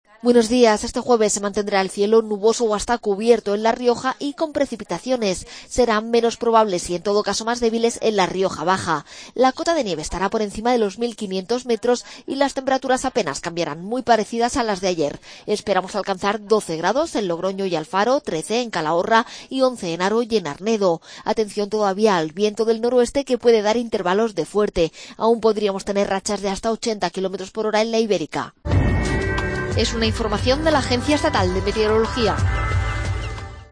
AUDIO: Pronóstico.